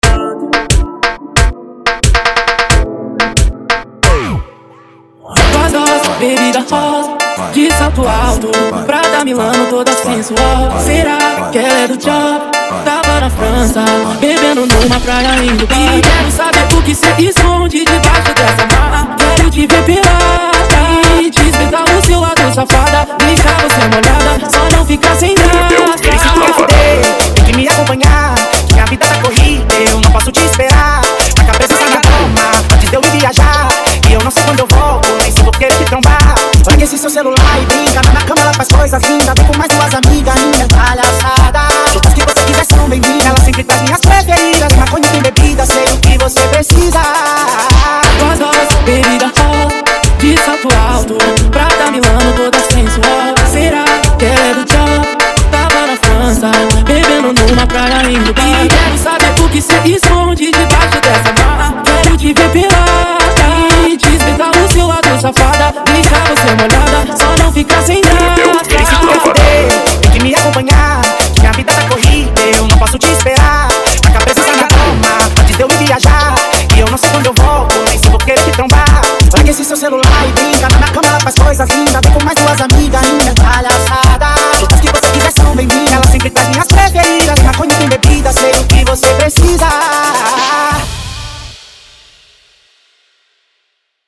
Tecno Melody 2023